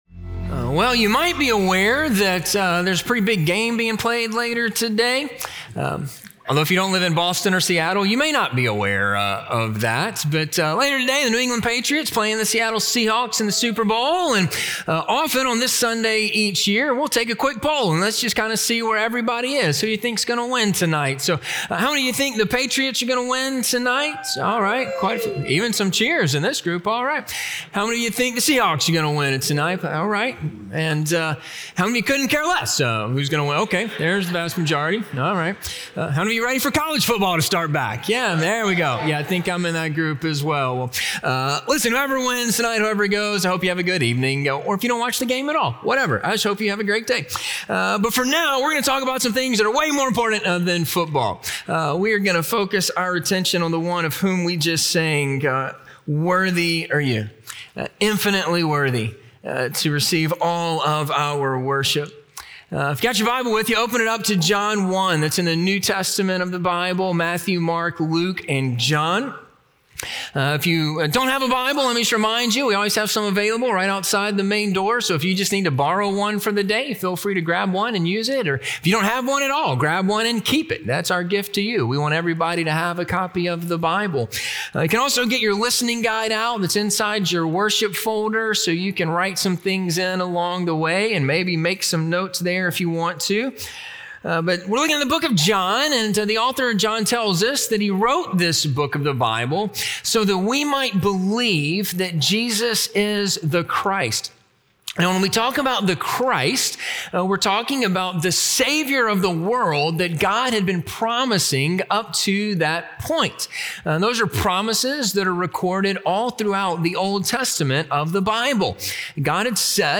The Lamb of God - Sermon - Ingleside Baptist Church